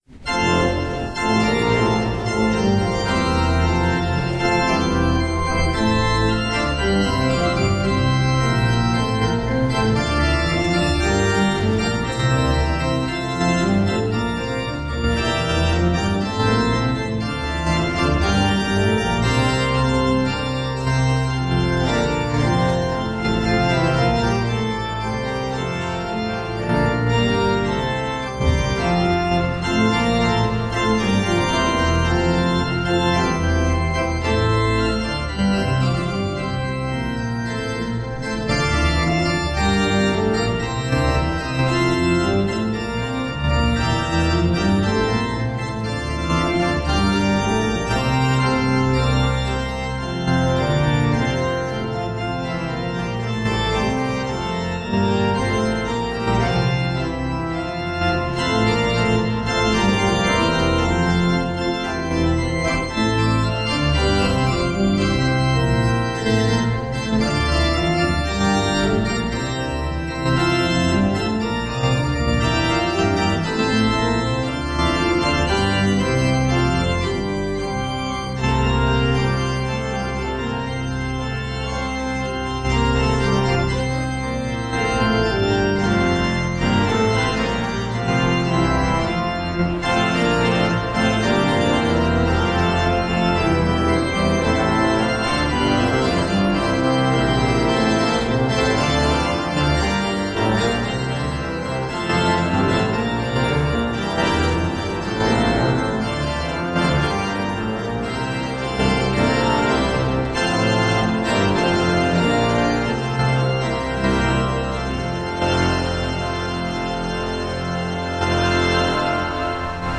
Hear the Bible Study from St. Paul's Lutheran Church in Des Peres, MO, from February 8, 2026.
Join the pastors and people of St. Paul’s Lutheran Church in Des Peres, MO, for weekly Bible study on Sunday mornings.